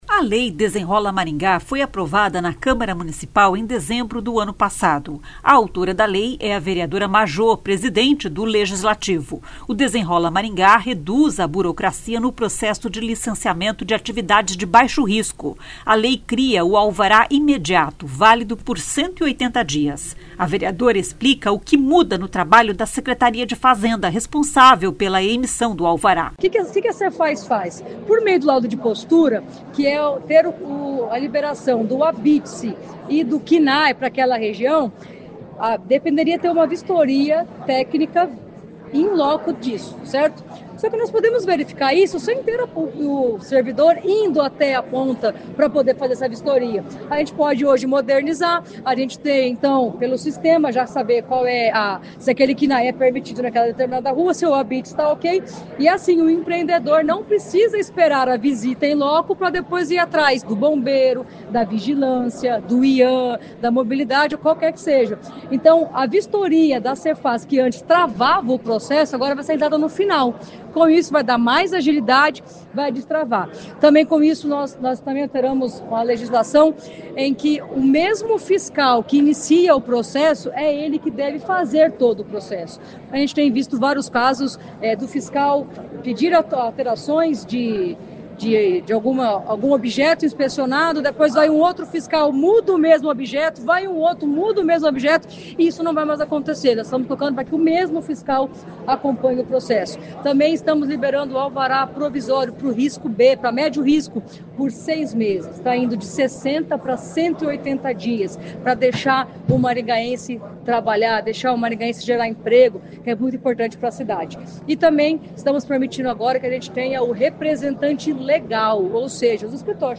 A vereadora explica o que muda no trabalho da Secretaria de Fazenda, responsável pela emissão do alvará.
O prefeito Silvio Barros diz que a lei trata apenas de questões burocráticas, sem prejuízo ao meio ambiente.